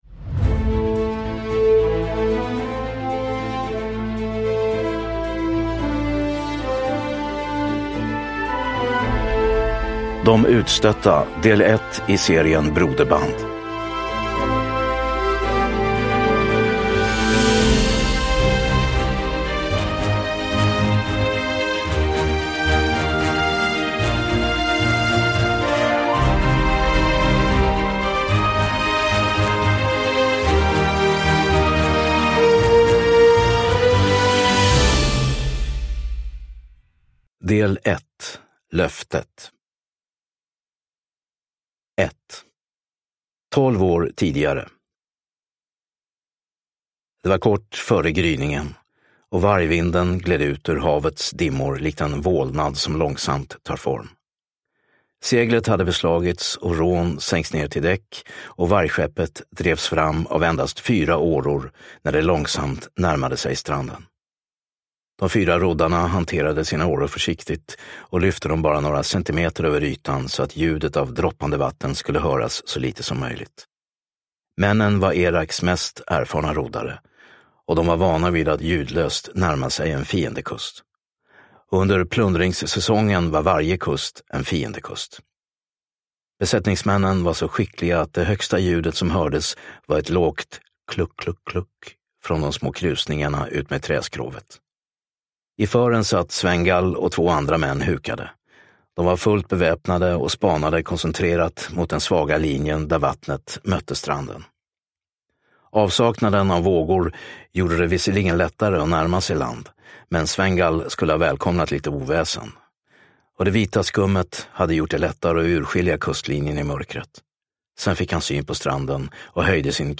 De utstötta – Ljudbok – Laddas ner